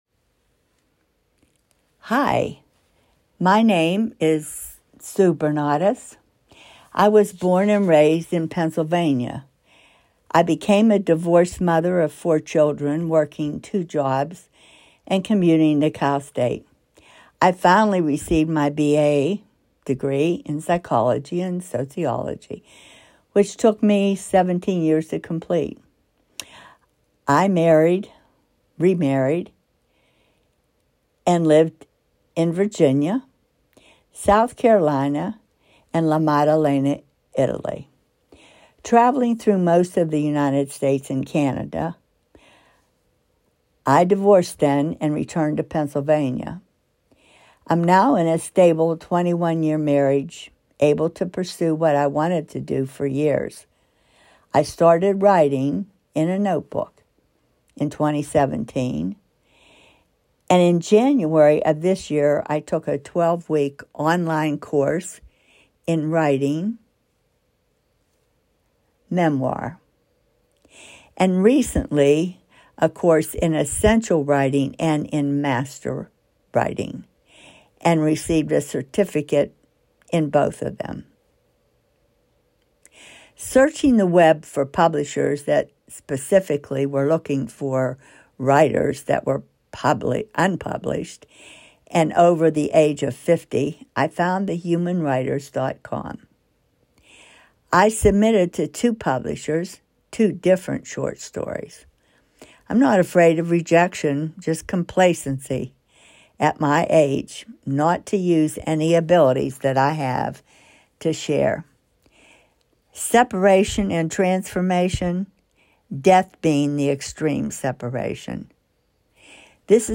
You can hear the author reading this story by clicking the below arrow: